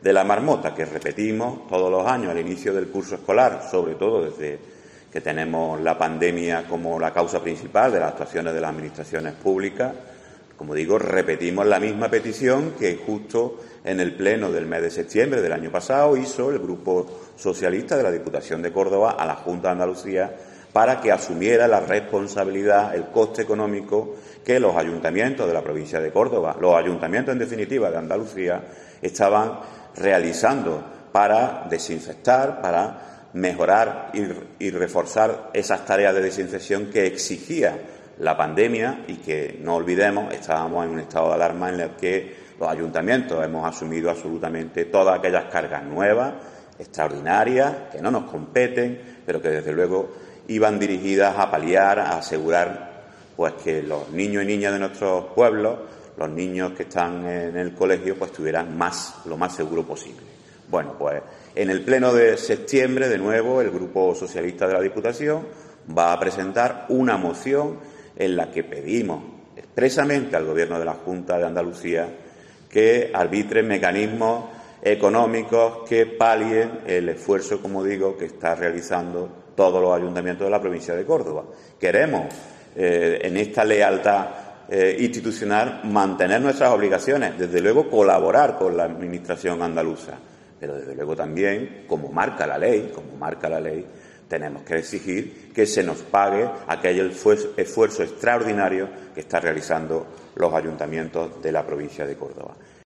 Así lo ha avanzado Esteban Morales en una rueda de prensa en la que ha explicado que "los ayuntamientos han venido haciendo frente en solitario a lo largo de los últimos 18 meses a los efectos que ha generado la enfermedad causada por el nuevo coronavirus conocido como Sars-CoV-2, denominada como Covid-19".